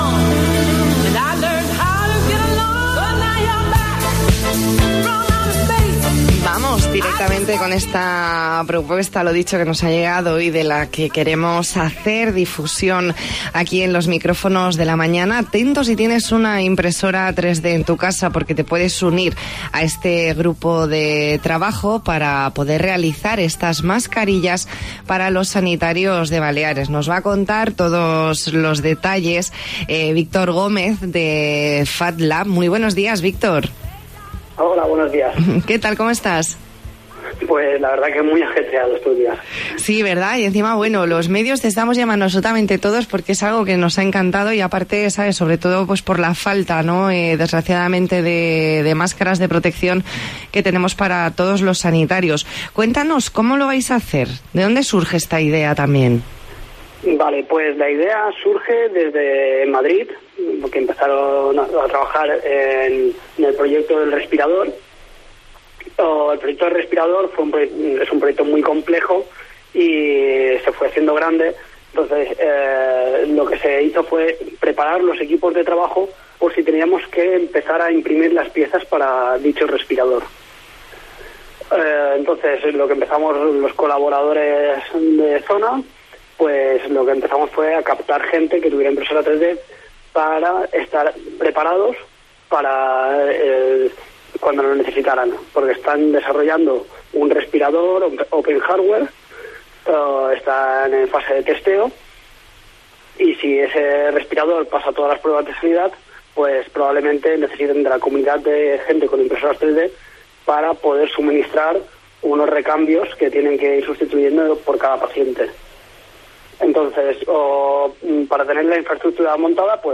Entrevista en La Mañana en COPE Más Mallorca, viernes 20 de marzo de 2020.